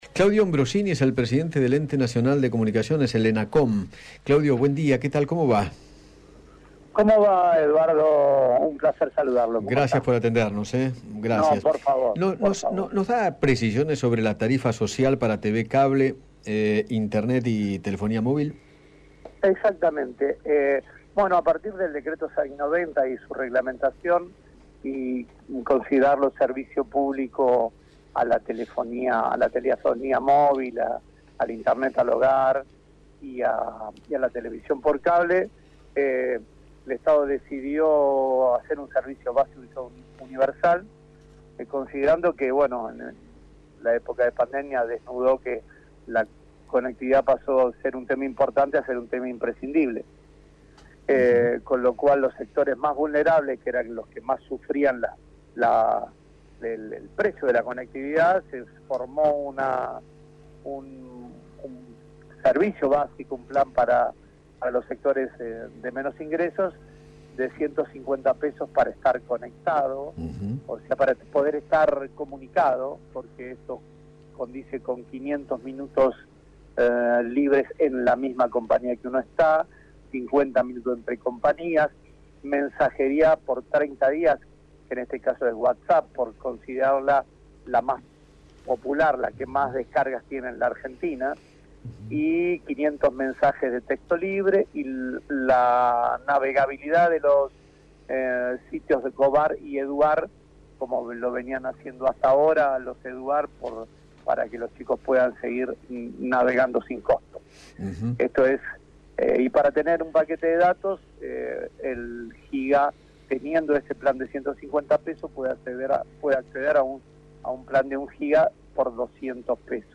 Claudio Ambrosini, presidente del ENACOM, dialogó con Eduardo Feinmann sobre la tarifa social que lanzó el Gobierno para los servicios de comunicaciones móviles, telefonía fija, conexión a Internet y televisión por suscripción, destinado a unos diez millones de beneficiarios.